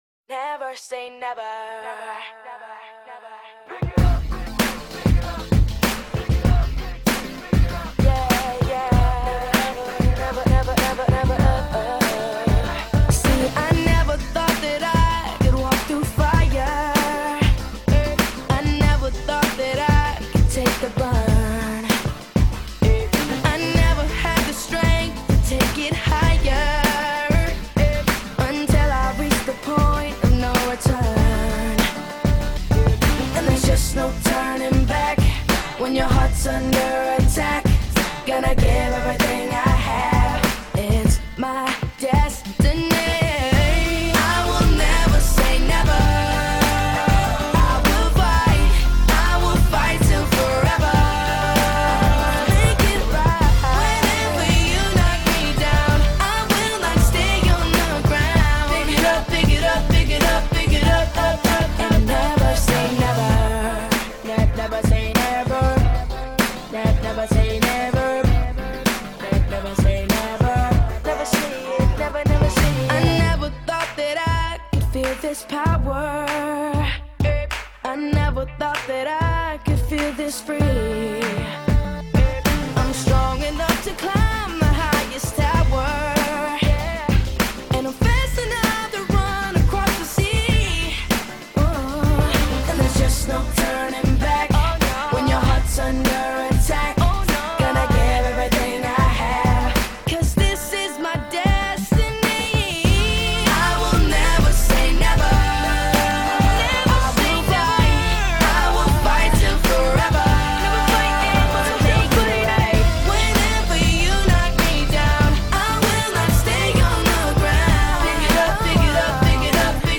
Категория: Клубная музыка